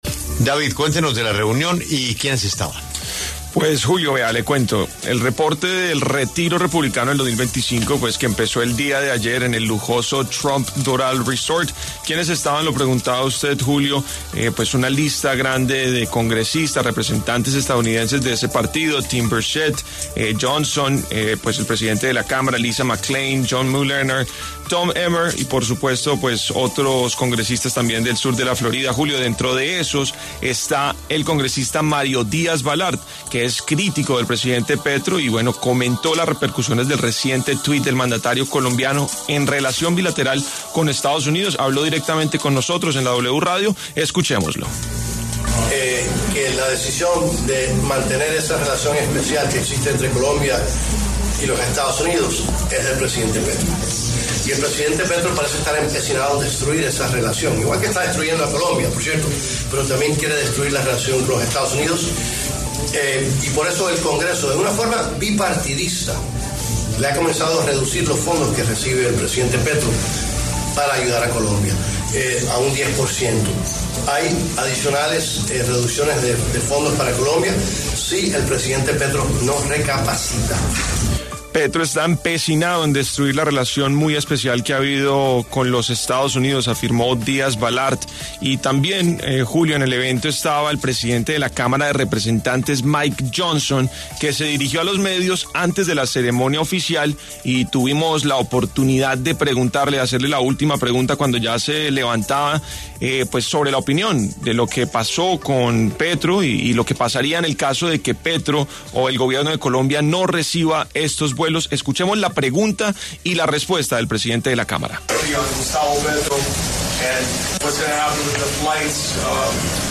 La W habló con los congresistas republicanos Mike Johnson y Mario Díaz-Balart, quienes se refirieron al conflicto diplomático entre los presidentes Trump y Petro por el caso de los migrantes irregulares deportados.
En el marco de un evento de congresistas republicanos que empezó ayer lunes 27 de enero en el lujoso Trump Doral Resort, La W tuvo la oportunidad de conversar con el republicano Mike Johnson, presidente de la Cámara de Representantes de Estados Unidos, quien se refirió al conflicto diplomático entre los presidentes Donald Trump y Gustavo Petro con respecto a la repatriación de colombianos deportados desde suelo estadounidense.